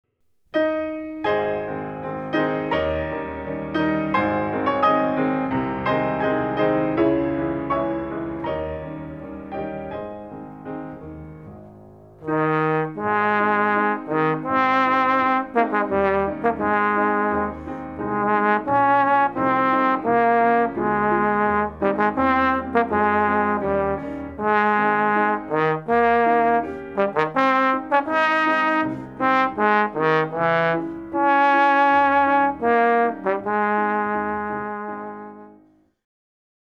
slag_snaar_blaasinstrument1.mp3